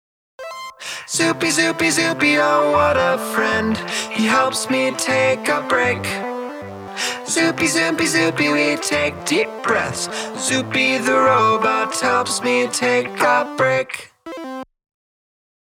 download sing along jingle